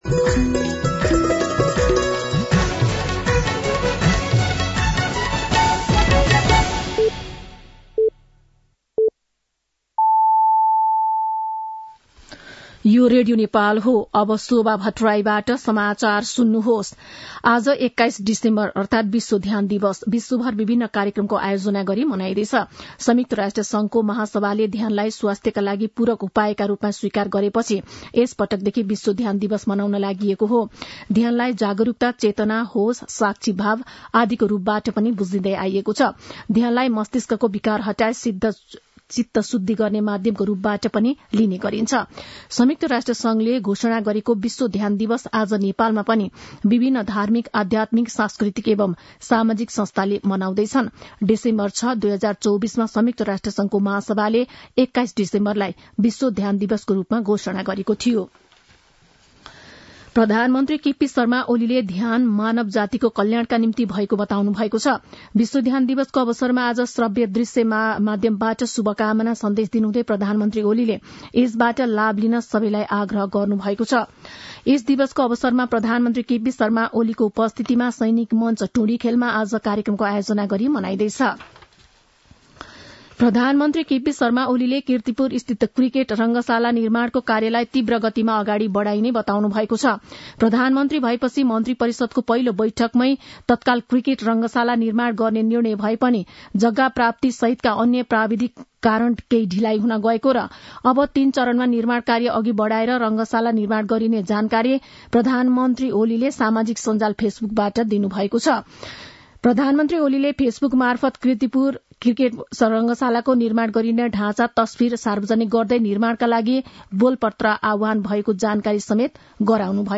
दिउँसो १ बजेको नेपाली समाचार : ७ पुष , २०८१
1-pm-Nepali-News-2.mp3